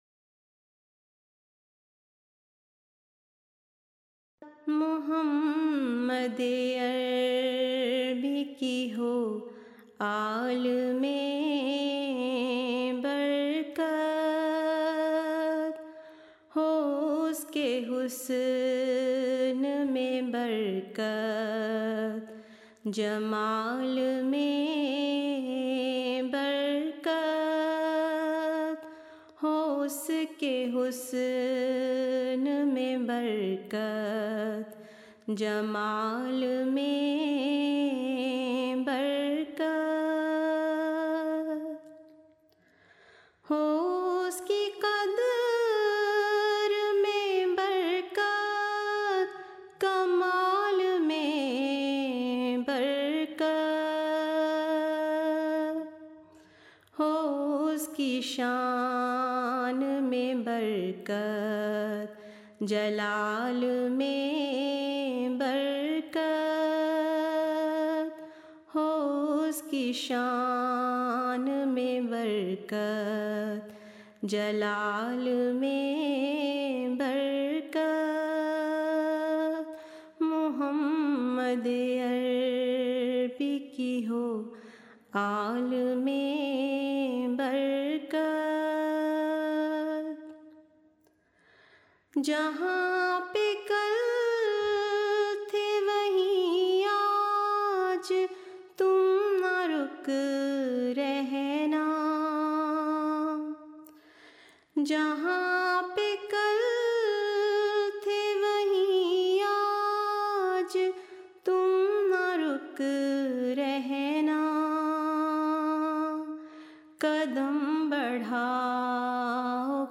Urdu Poems
(Jalsa Salana UK)